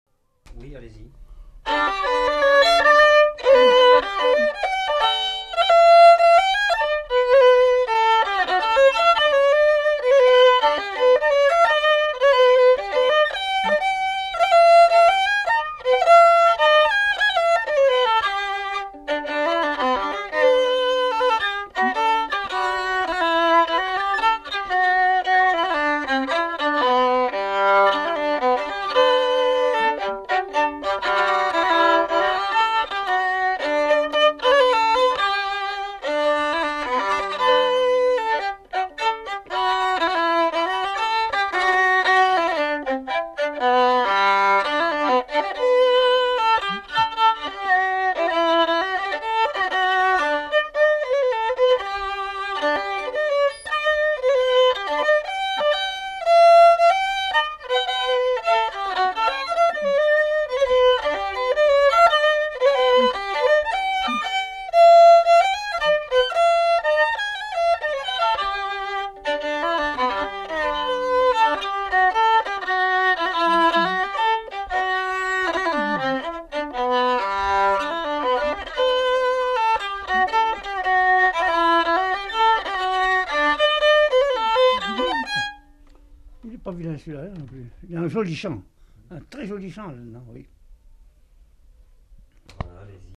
Aire culturelle : Haut-Agenais
Lieu : Castillonnès
Genre : morceau instrumental
Instrument de musique : violon
Danse : rondeau